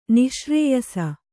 ♪ nih śrēyasa